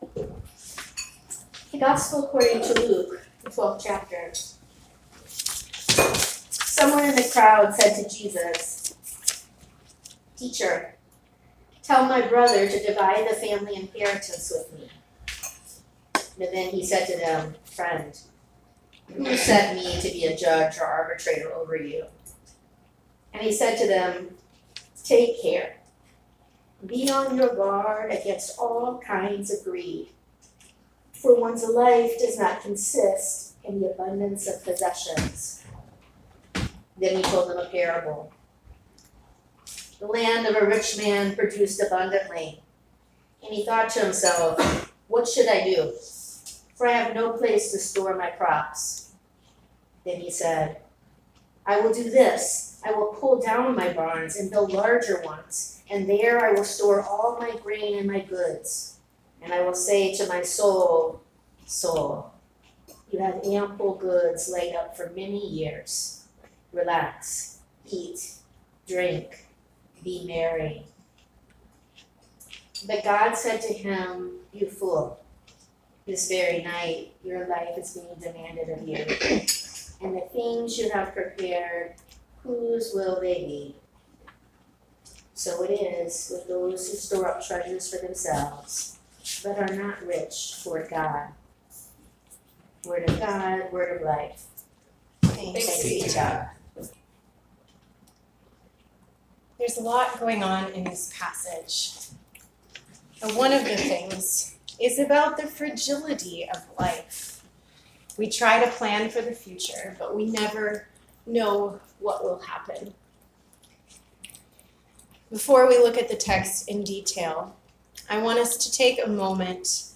June 16, 2019 Sermon